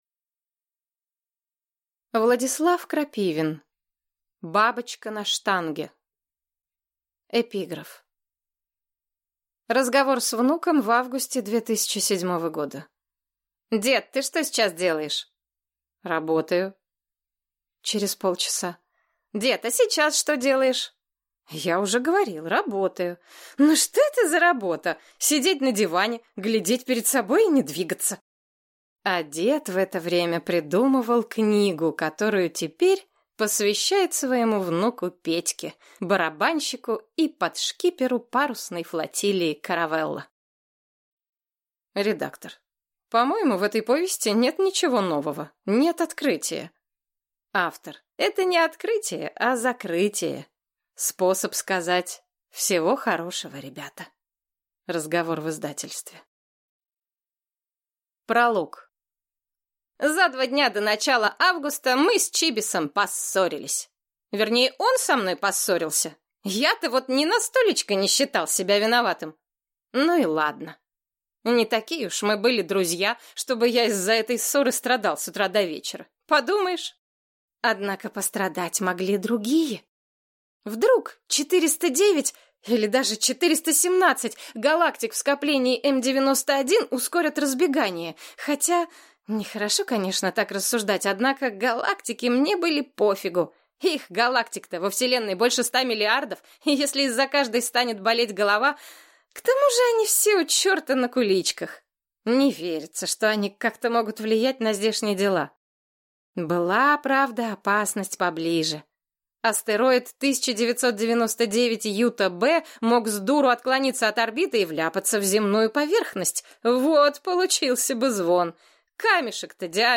Аудиокнига Бабочка на штанге | Библиотека аудиокниг